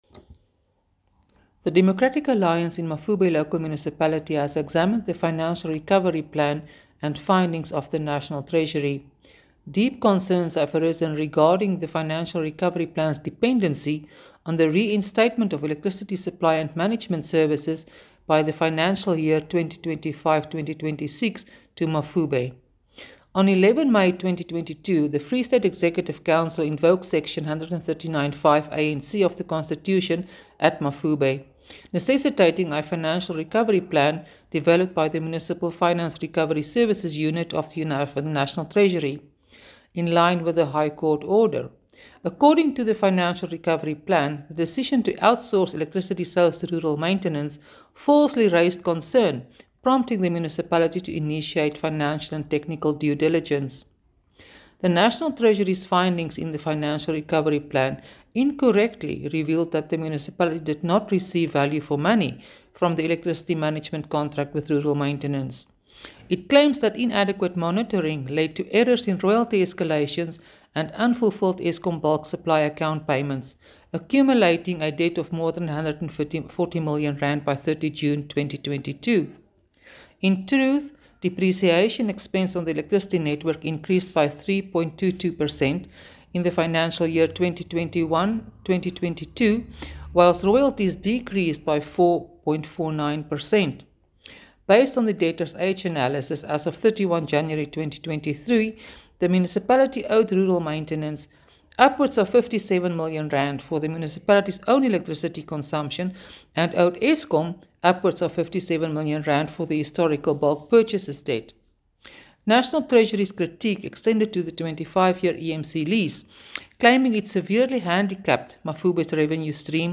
Issued by Suzette Steyn – DA Councillor Mafube Local Municipality
Afrikaans soundbites by Cllr Suzette Steyn and Sesotho by Karabo Khakhau MP.